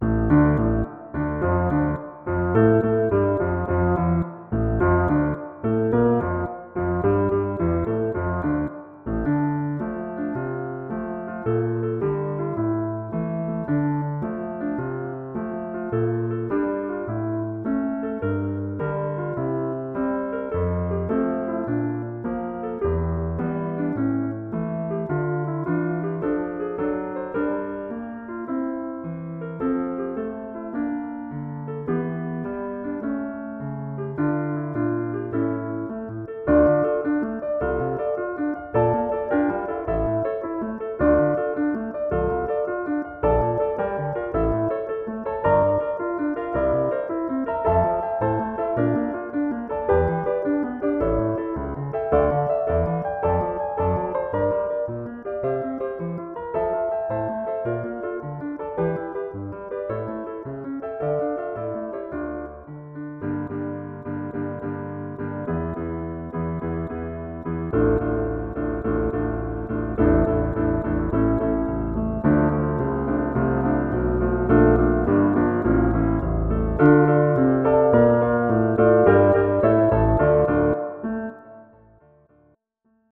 I made a few changes to the tuning.